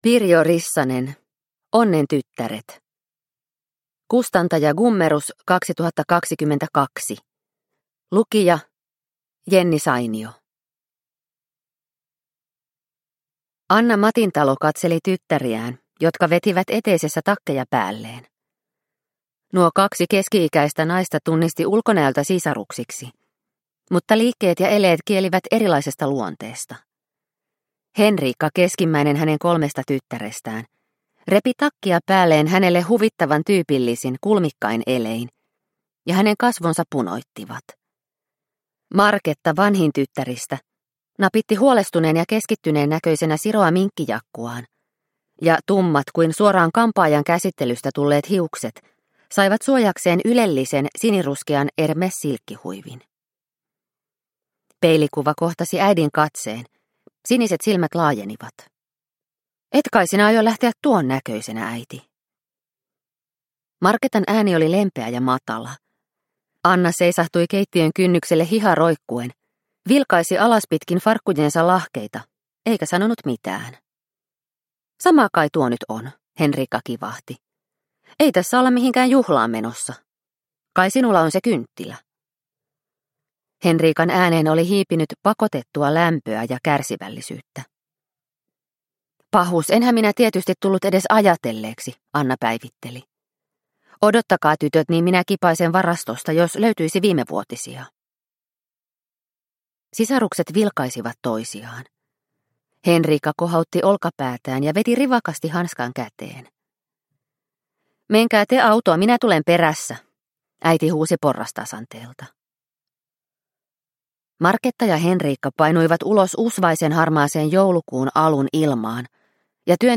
Onnen tyttäret – Ljudbok – Laddas ner